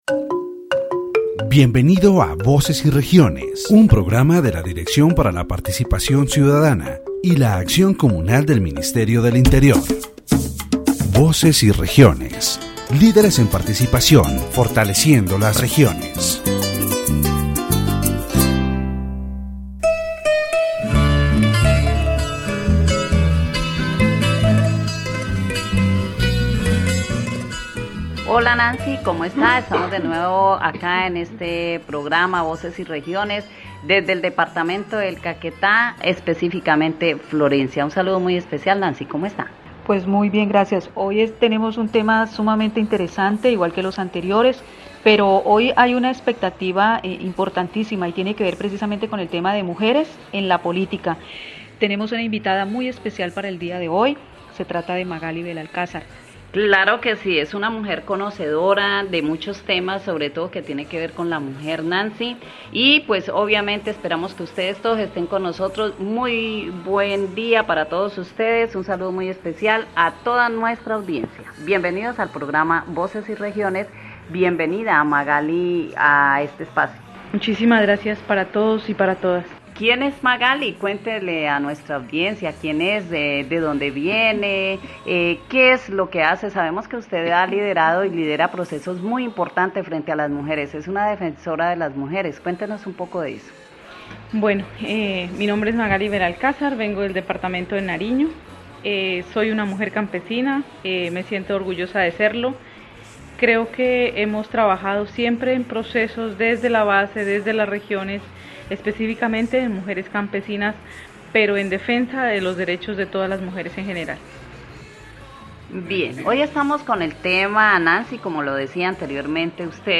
The radio program "Voices and Regions" focuses on the participation of women in politics, highlighting the importance of their inclusion in decision-making spaces and the fight against gender violence.